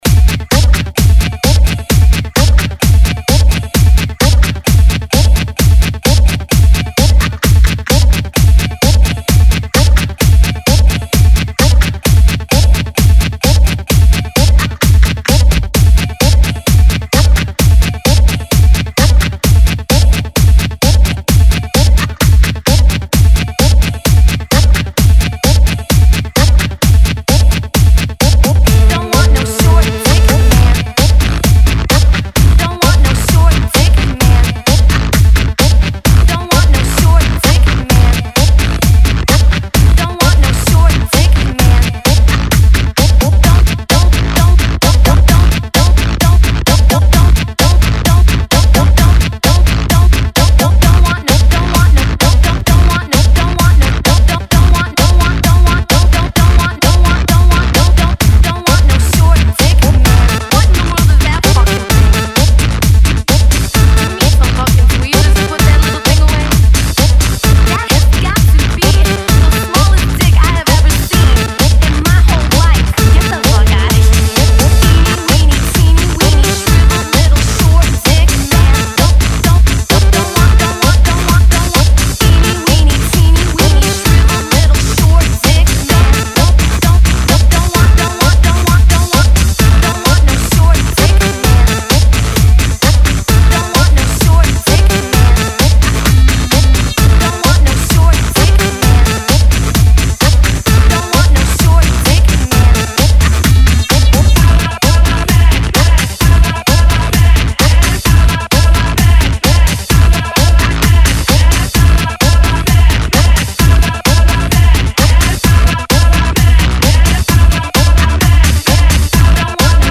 Жанр:Club House